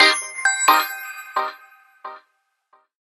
جلوه های صوتی
دانلود صدای هشدار موبایل 21 از ساعد نیوز با لینک مستقیم و کیفیت بالا